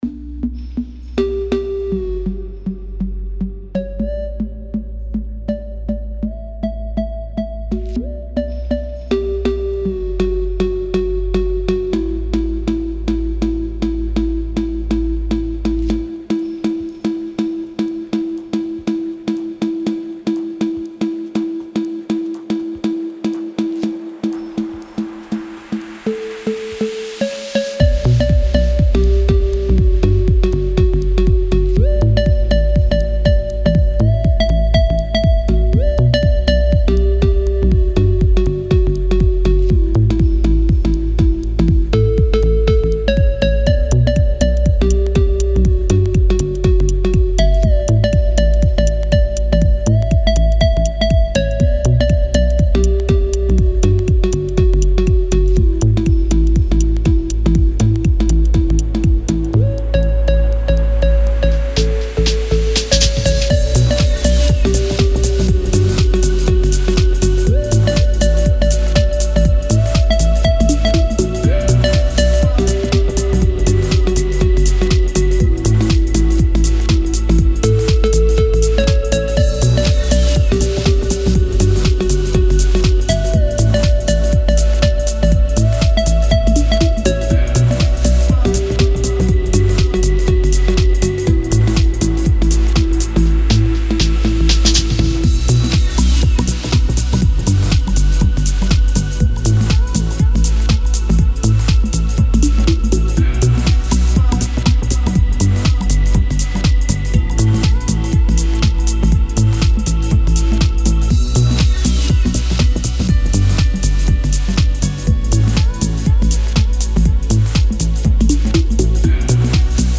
Категория: Электро музыка » Чилаут и лаунж